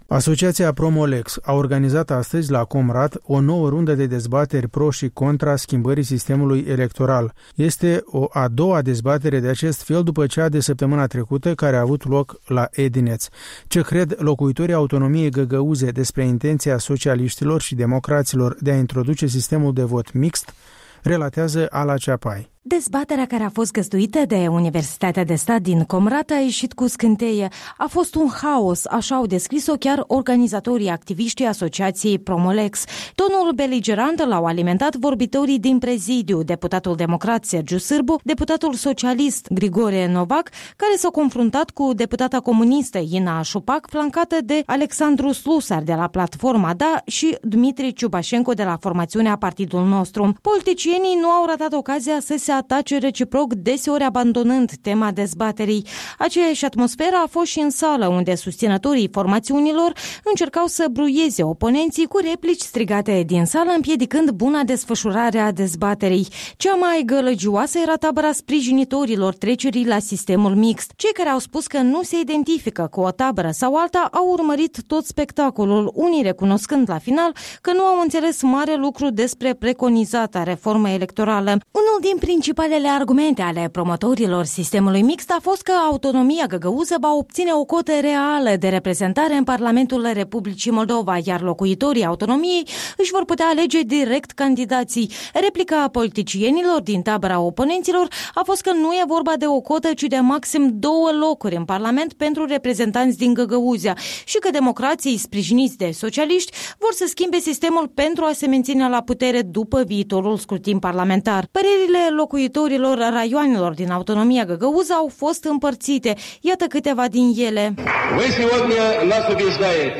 Haos la o dezbatere Promo-Lex la Comrat
O dezbatere încinsă în Autonomia găgăuză